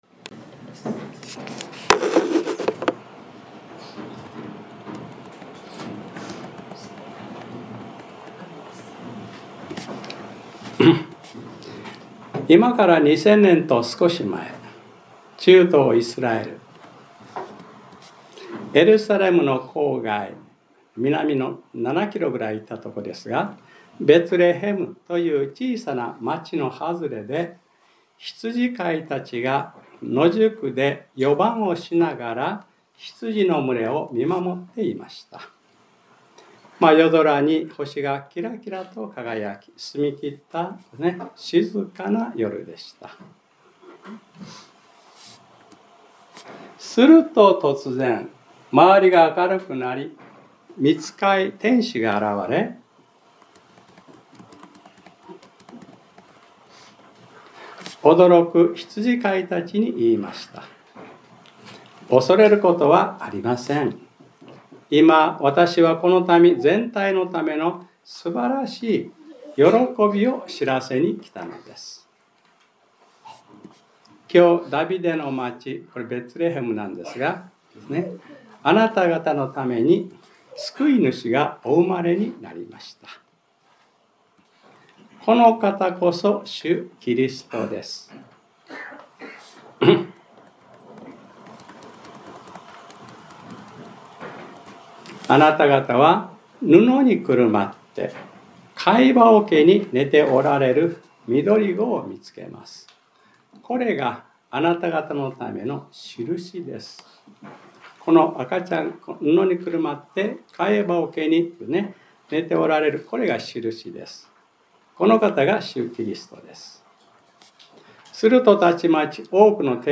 2025年12月21日（日）礼拝説教『 暗闇に光が 』